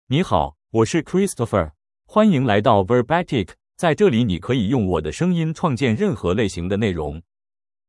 ChristopherMale Chinese AI voice
Christopher is a male AI voice for Chinese (Mandarin, Simplified).
Voice sample
Listen to Christopher's male Chinese voice.
Male